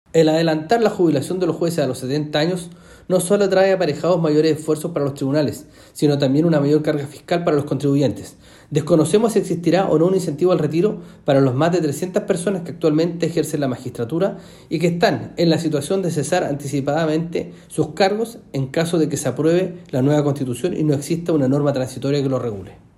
Roberto Vega, constituyente de Renovación Nacional, señaló que en su sector también abogarán por la gradualidad, aunque no saben aún qué normativa apoyarán.